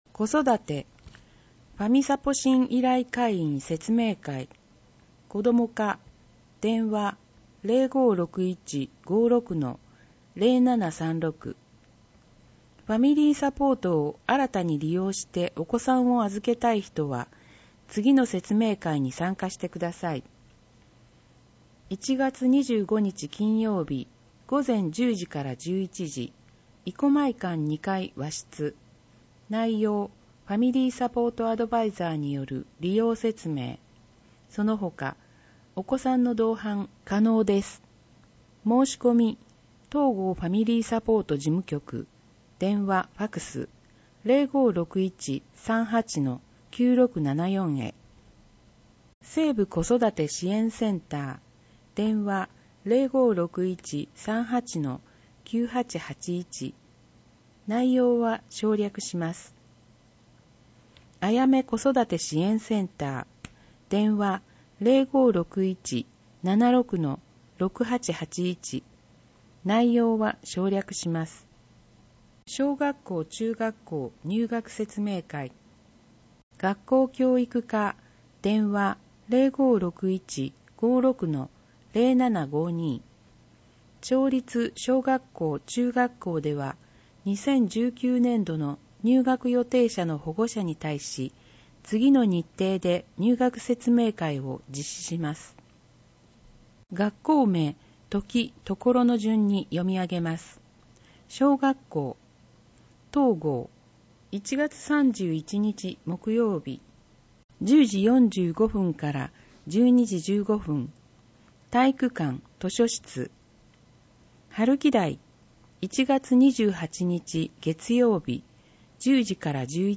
広報とうごう音訳版（2019年1月号）